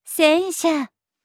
TTS comparison (Pitch Accent)
VoicePeak (Haruno Sora, CV: Inoue Kikuko)
1-Haruno-Sora-戦車-example.wav